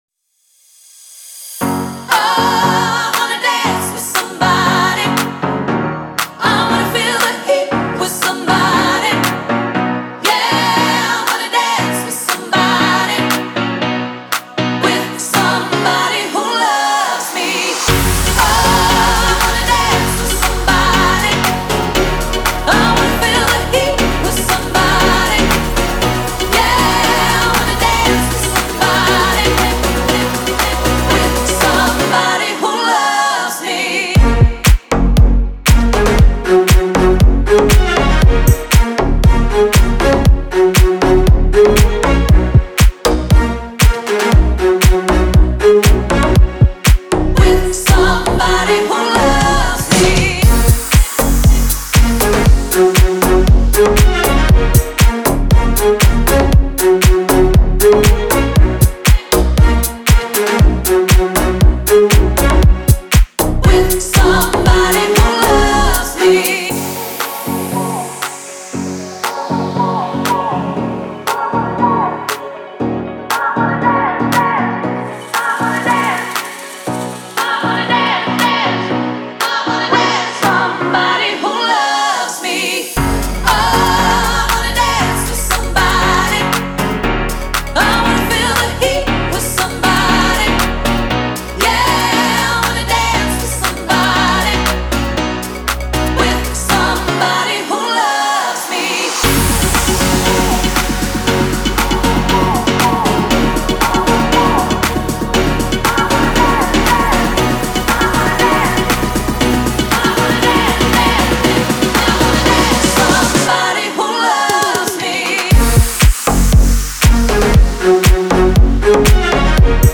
это яркая и энергичная танцевальная композиция в жанре поп